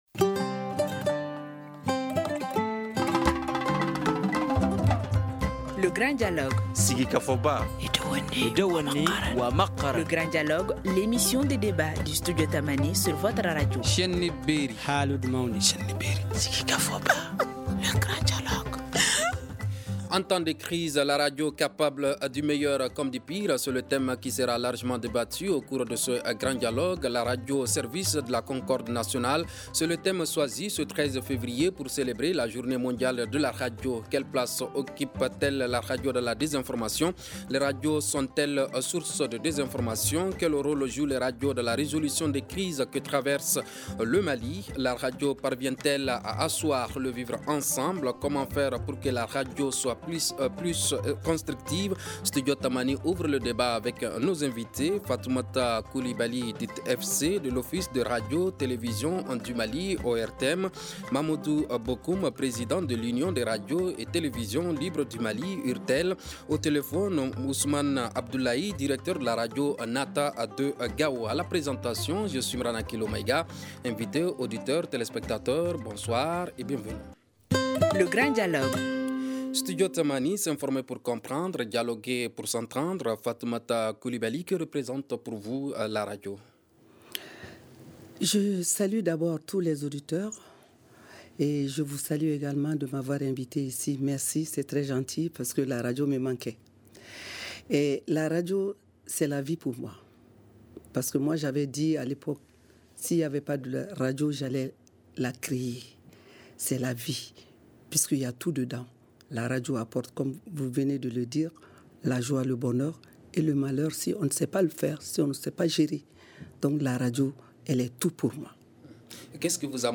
Studio Tamani ouvre le débat